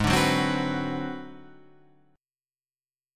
Ab7#9b5 chord